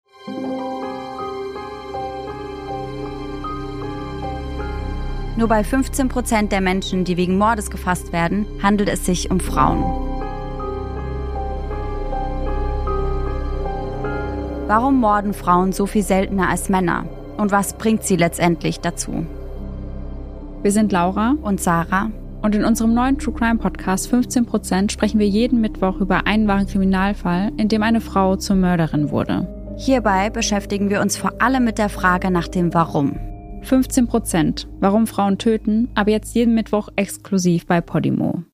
Trailer: „15% - Wenn Frauen töten"
True Crime